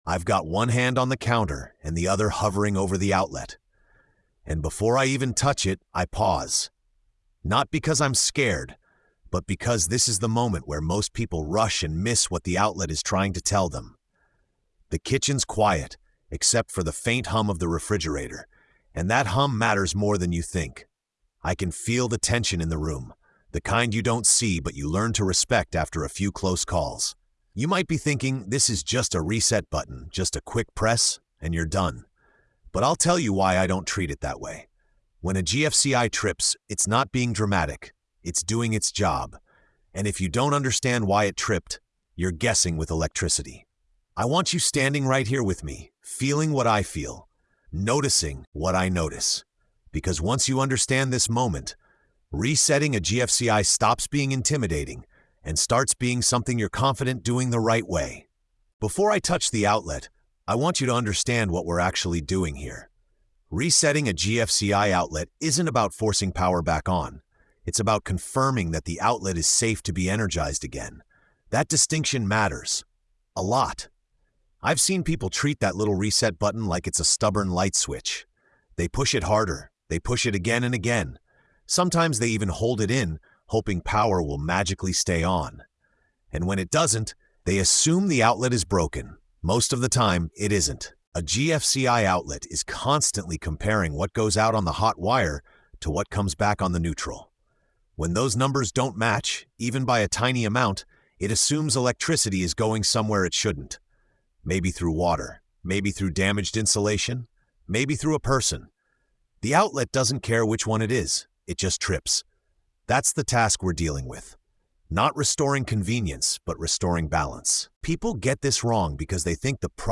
Through calm, grounded teaching and real job-site experience, the narrator shows how to read the signs, recognize danger versus false alarms, and understand why GFCI outlets trip in the first place.